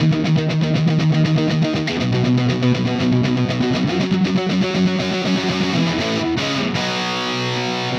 Power Pop Punk Guitar 02d.wav